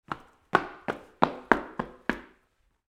Shaking Snow Off Shoes Sound Effect
A realistic foley recording of snow being shaken and kicked off boots or shoes. This sound captures the rhythmic thudding and crunching of winter footwear being cleaned against a hard surface, perfect for winter-themed scenes, transitions, or outdoor soundscapes.
Shaking-snow-off-shoes-sound-effect.mp3